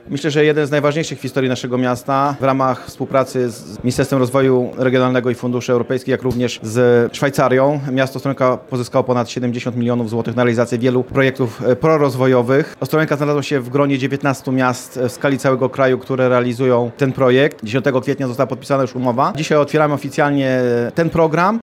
Prezydent Ostrołęki Paweł Niewiadomski ocenił, że realizowany projekt ma ogromne znaczenie dla miasta.